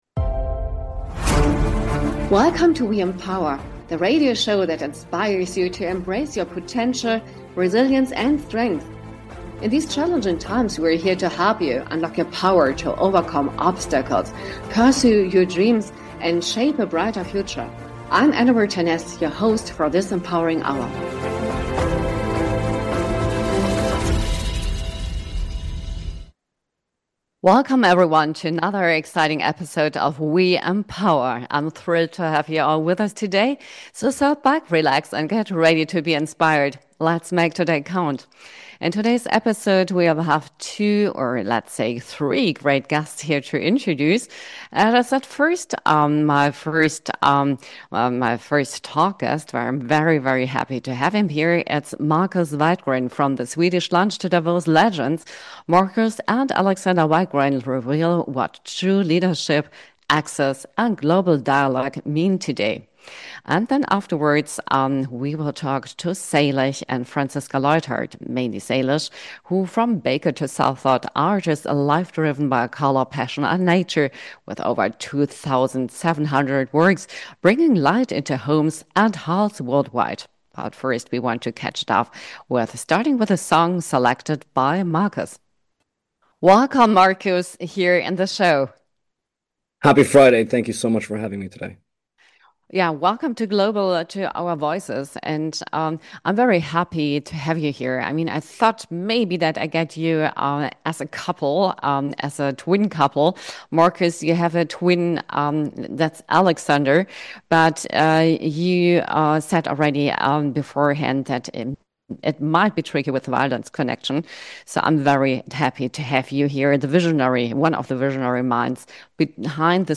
Welcome to "WE EMPOWER" – a radio show inspiring women to unleash their strengths and thrive in various life aspects. Featuring interviews with impressive female personalities across professions and discussions on women-led businesses, the show celebrates pioneers, especially ...